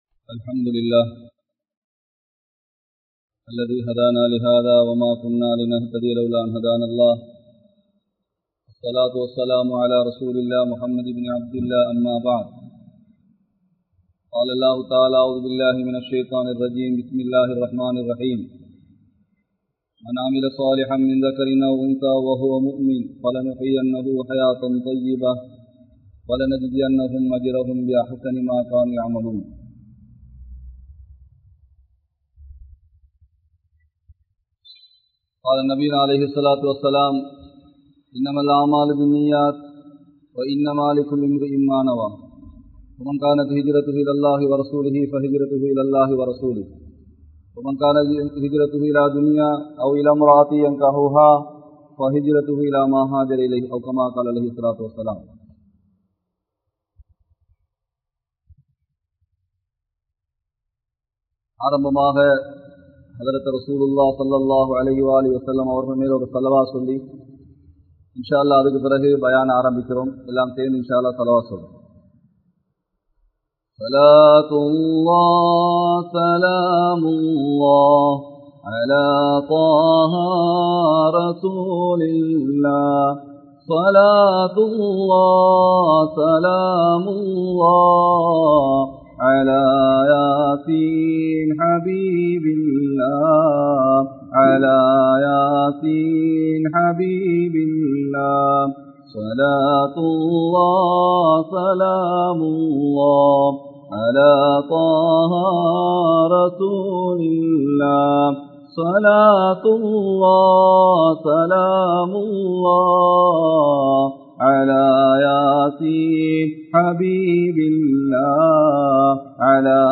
Ungalil Yaar Siranthavar | Audio Bayans | All Ceylon Muslim Youth Community | Addalaichenai